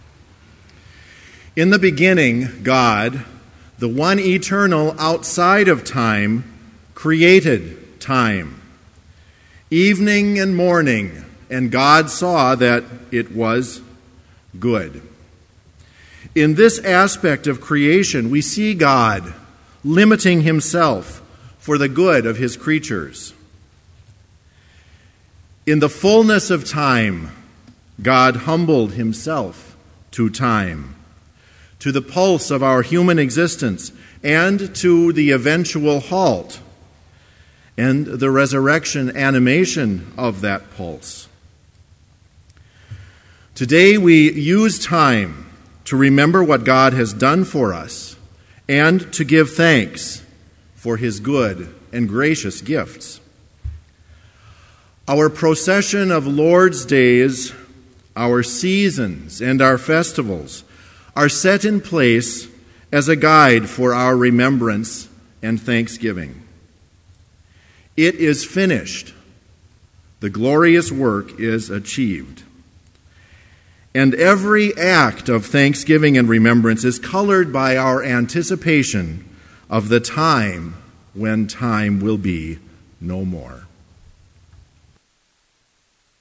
Sermon Only
This Chapel Service was held in Trinity Chapel at Bethany Lutheran College on Tuesday, December 4, 2012, at 10 a.m. Page and hymn numbers are from the Evangelical Lutheran Hymnary.